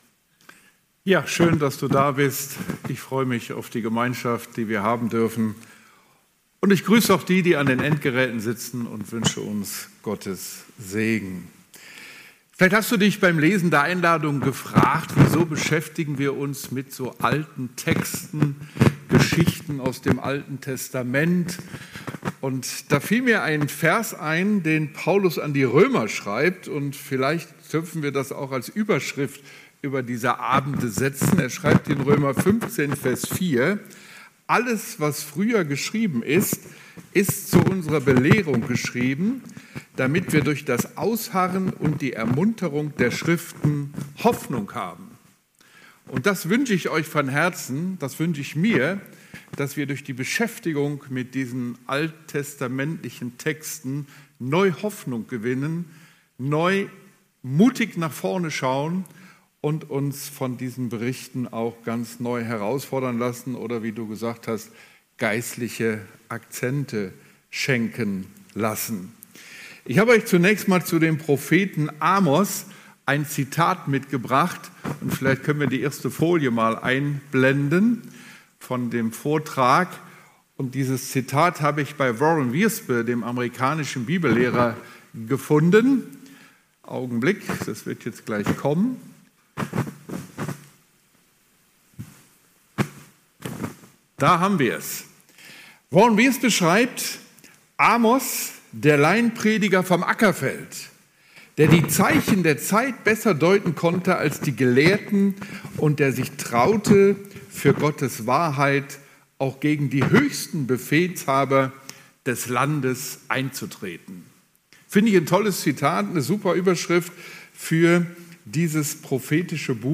23.07.2025 Themenabend #1 ~ Predigten - FeG Steinbach Podcast